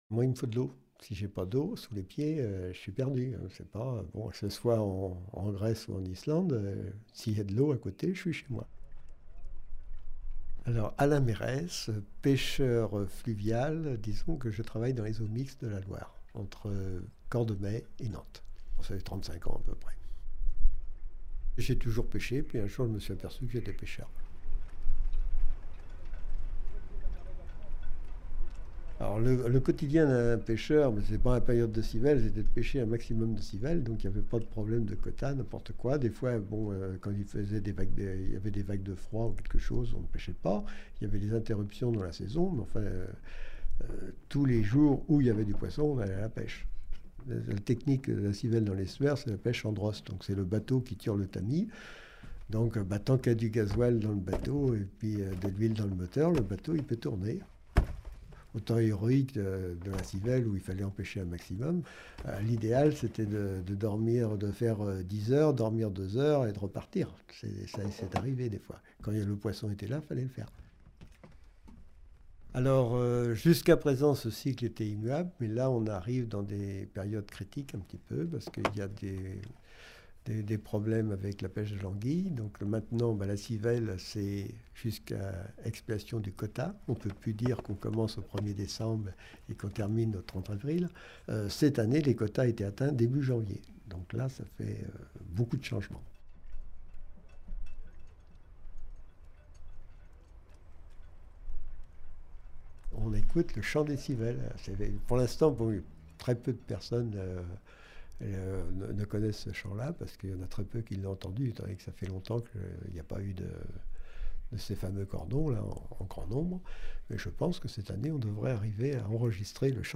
Portraits sonores